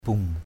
/buŋ/ (d.) bồ để đựng lúa = grand panier tressé pour contenir le riz. katrau kau sa bai, padai kau sa bung kt~@ k~@ s% =b, p=d k~@ s% b~/...